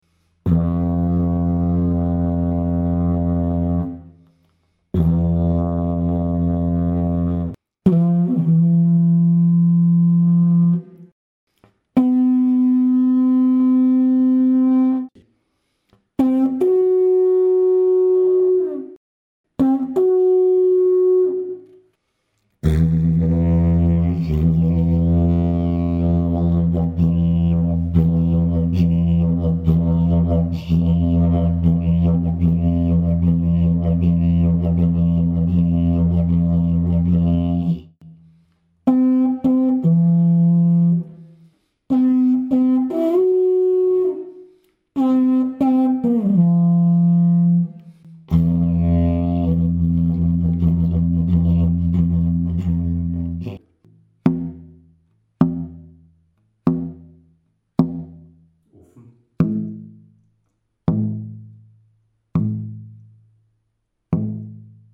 is a smaller didgeridoo with a pitch of F2, but with a concert pitch of 432 Hz, so a slightly lower F2.
F2-30 (E+30 to F+10) // F3-30 / C4-30 / F4#-10    (suitable for concert pitch 432 Hz)
is a technical sample to show the overtones, the plop resonance and the range to pull the basic key